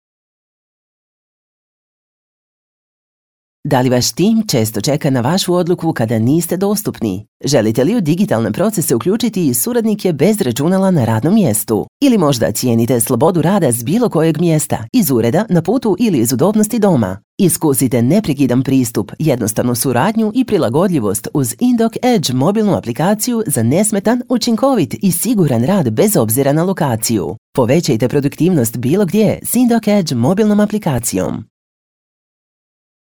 Dynamic, commercial, warm, friendly, and inspiring voice with different shades according to the client's needs.